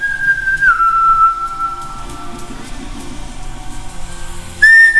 Звук свиста под музыку